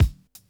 REG_KICK.WAV